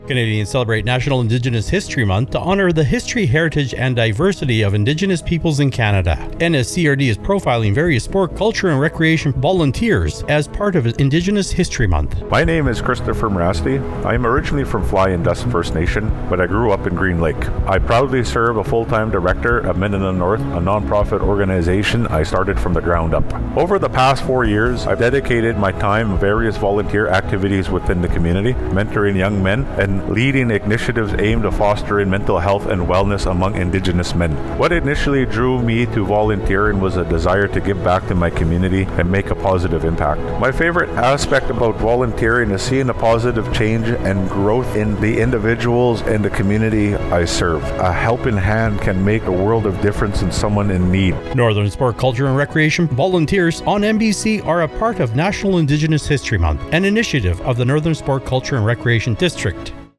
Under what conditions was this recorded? A partnership with MBC Radio.